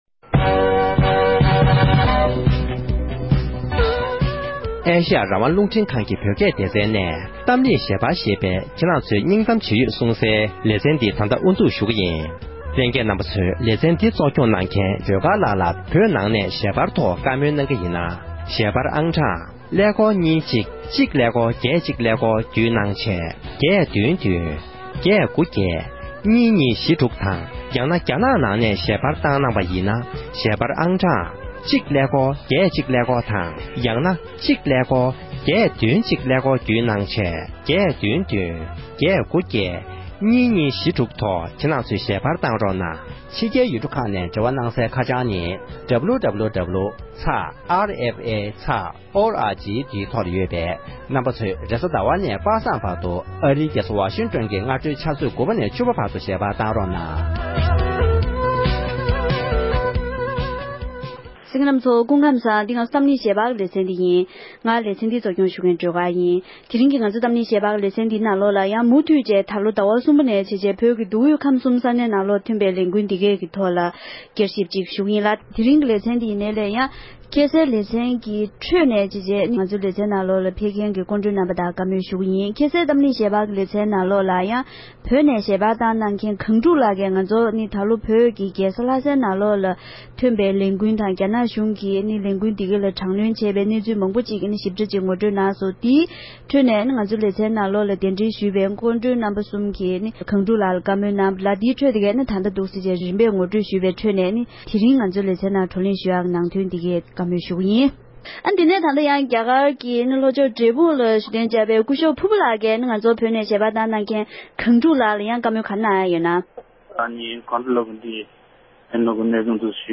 ༄༅༎དེ་རིང་གི་གཏམ་གླེང་ཞལ་པར་གྱི་ལེ་ཚན་ནང་དུ་འདི་ལོ་བོད་ཀྱི་མདོ་དབུས་ཁམས་གསུམ་ནང་ཐོན་པའི་ལས་འགུལ་ཁག་གི་ཐོག་བསྐྱར་ཞིབ་དང་ལོ་གསར་པའི་ནང་འཆར་གཞི་གང་ཡོད་སོགས་འབྲེལ་ཡོད་གནས་ཚུལ་ཁག་གི་ཐོག་བགྲོ་གླེང་ཞུས་པ་མུ་མཐུད་ནས་གསན་རོགས་གནང༌༎